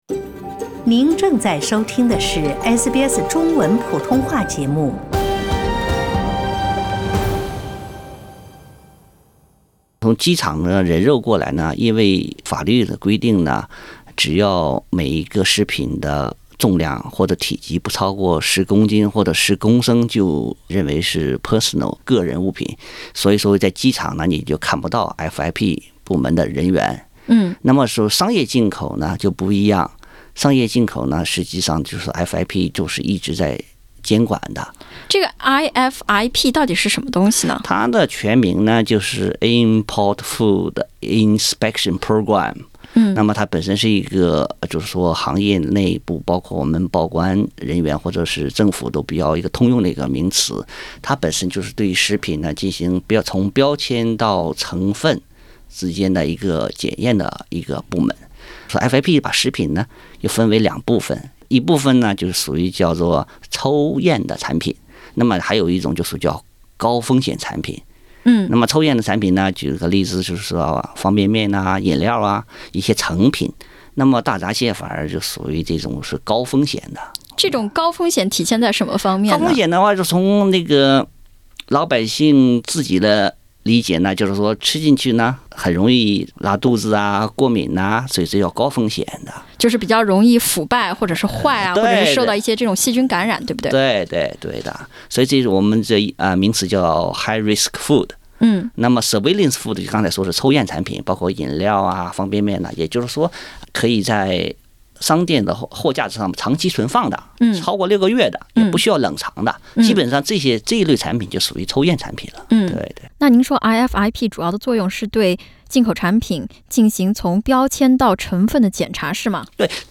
详细内容请点击封面图片收听采访。